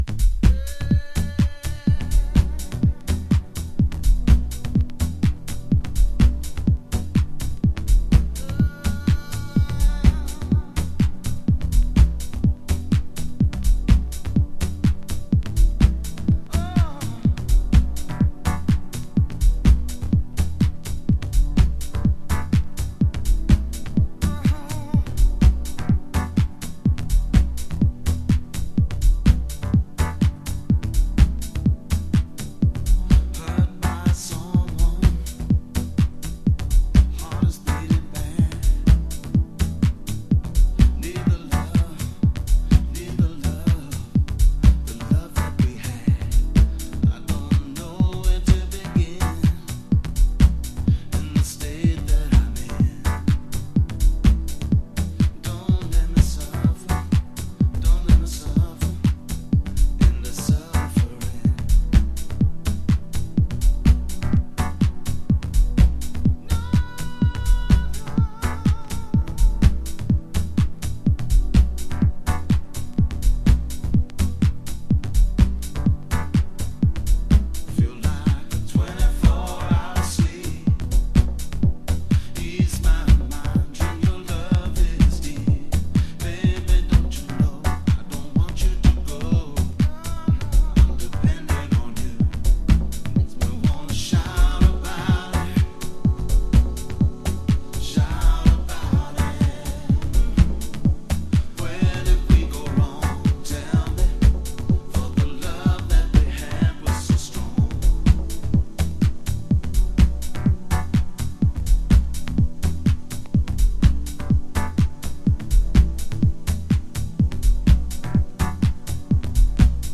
Early House / 90's Techno
都会的な哀愁が滲み出た、当時のハウスの本気度が伺える傑作です。
Club Vocal Mix